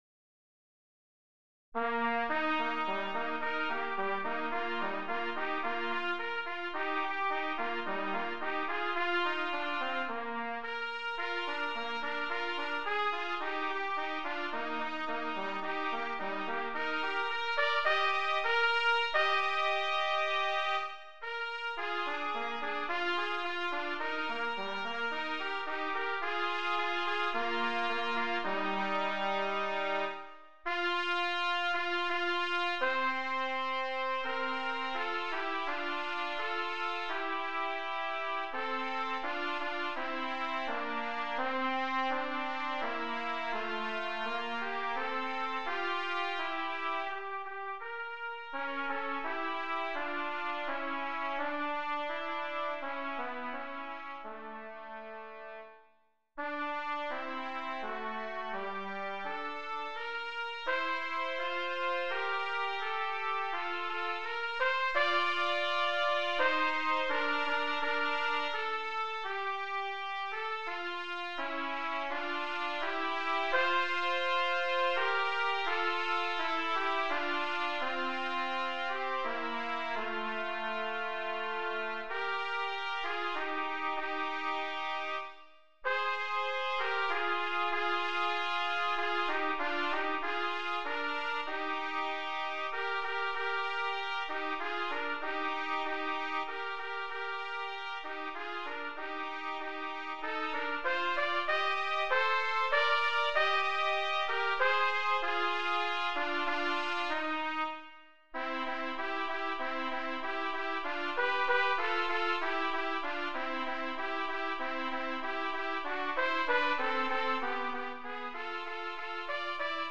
2 Trumpets